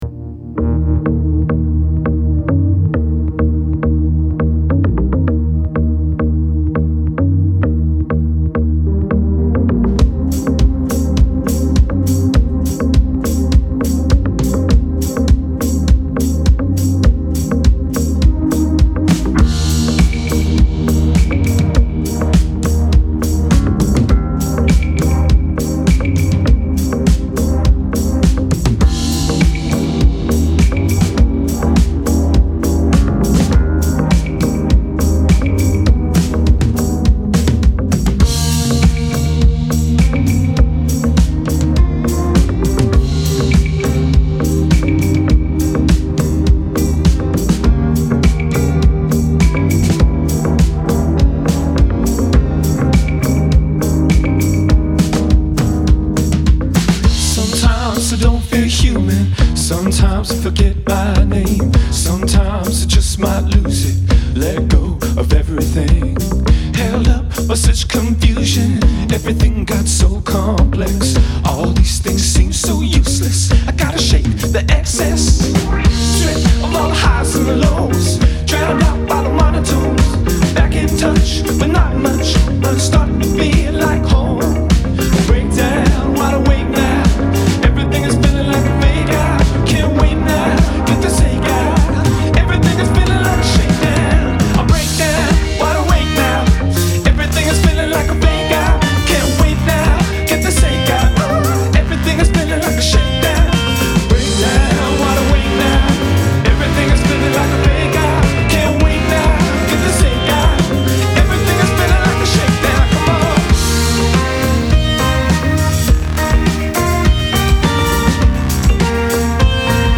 indie dance pop w/ musical flair